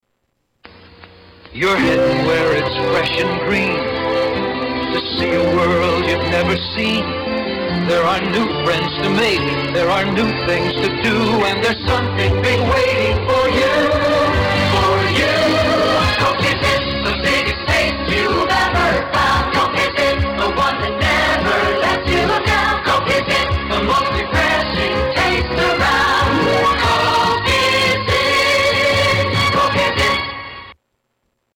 Commercials Jingles